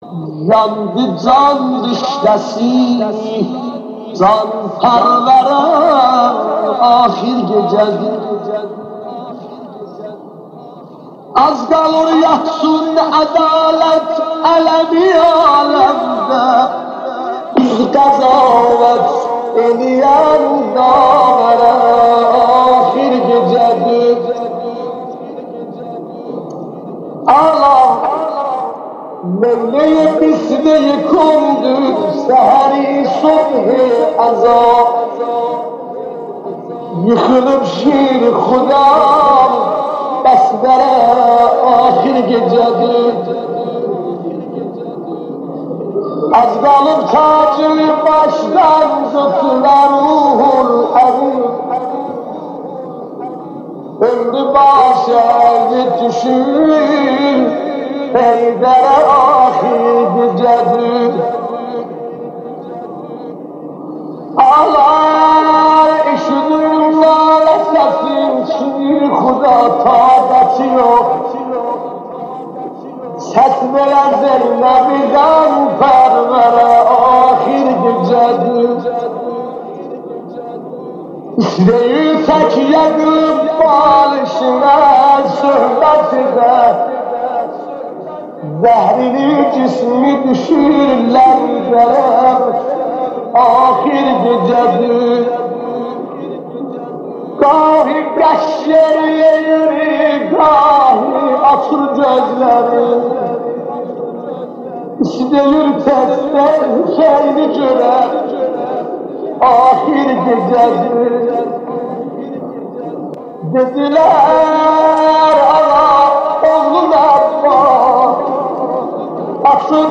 نوحه جان‌سوز